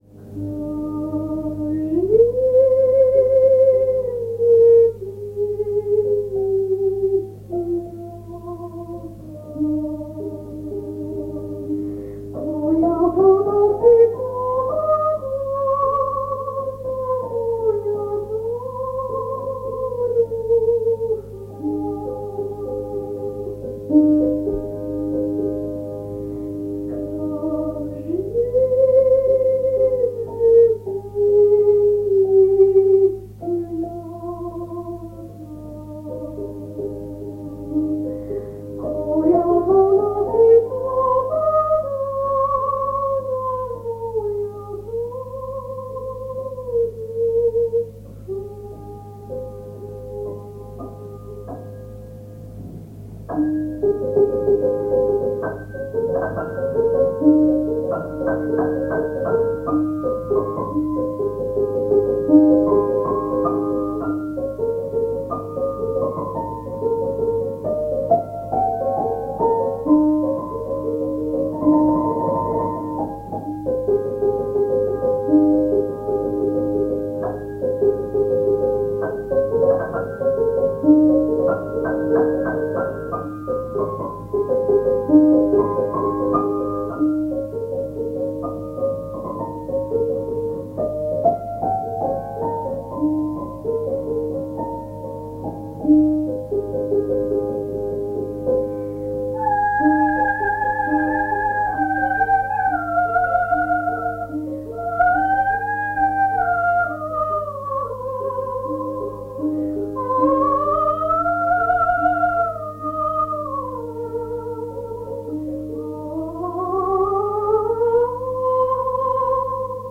Il canto Kaži mi Ti Istinata – III include l’originale del 1924 a cui è aggiunto un assolo strumentale composto da Simeon Simeonov.